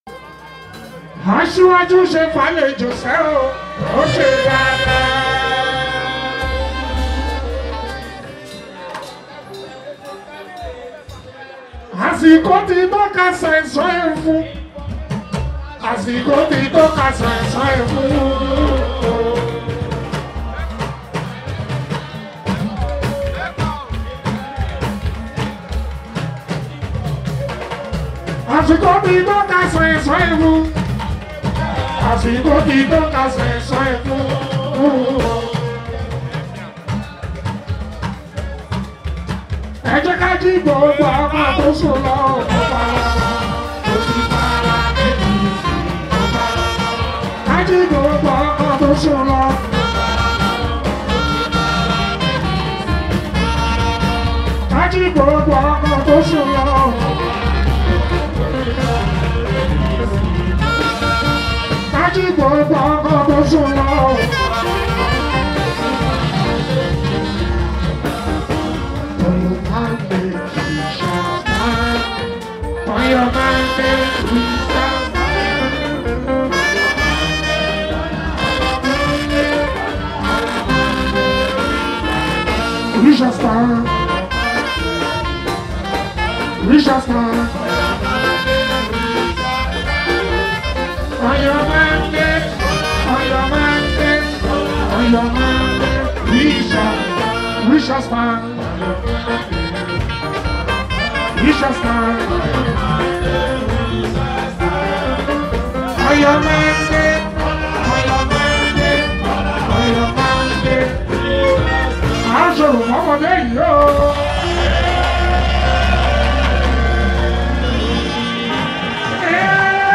Fuji, Highlife
Yoruba Fuji Hit song
Nigerian Yoruba Fuji track